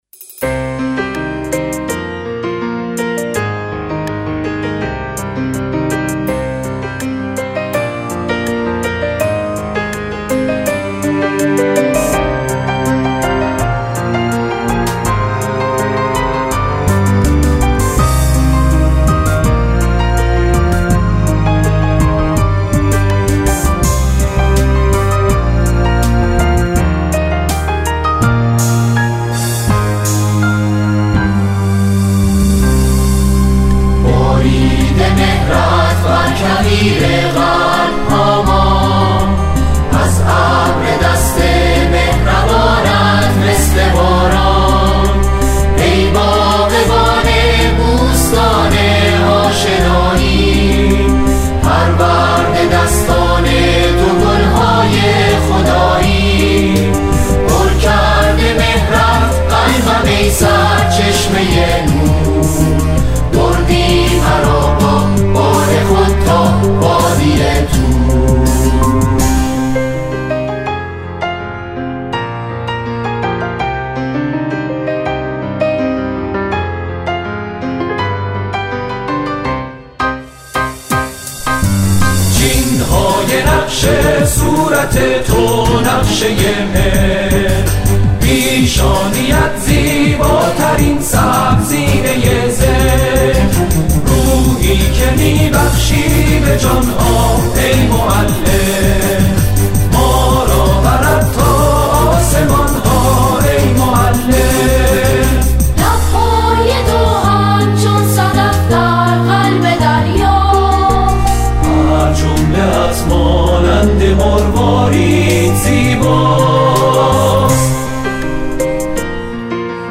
سرودهای روز معلم
به صورت جمعخوانی به اجرا رسید